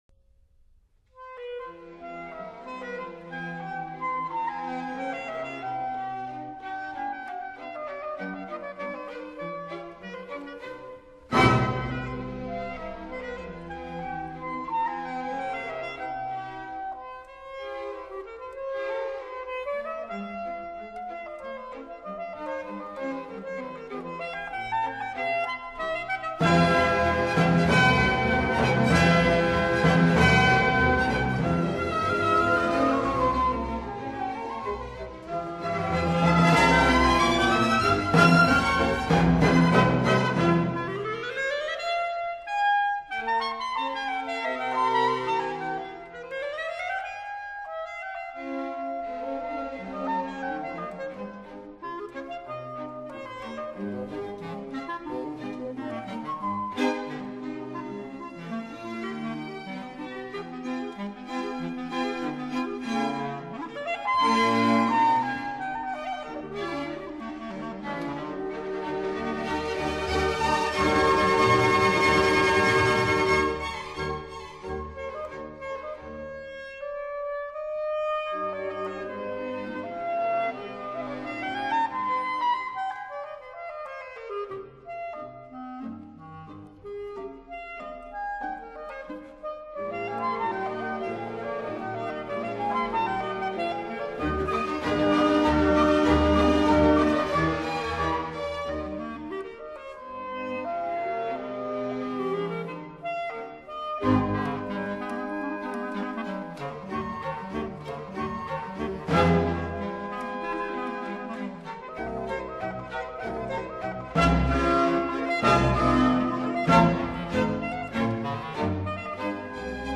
Rondo Allegretto